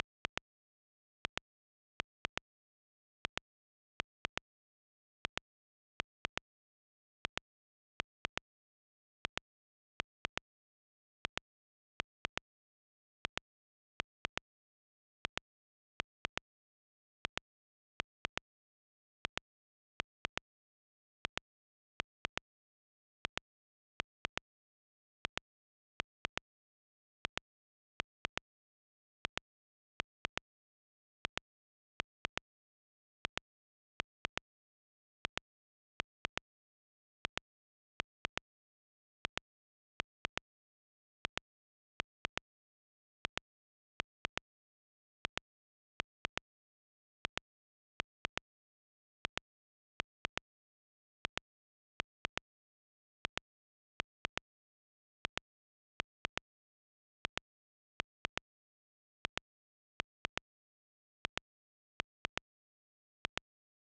This is a glitch sound that could potentially be used as a high-hat/high pitched rhythm sound.
After cropping the clap I placed it into the sampler and turned on the filter envelope and mod oscillator, transposing the pitch up 48 semitones.
After modifying the sampler I added reverb and a saturation.